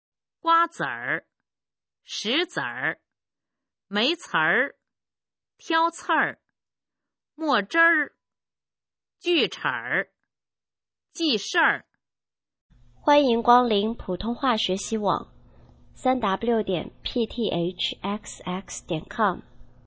普通话水平测试用儿化词语表示范读音第12部分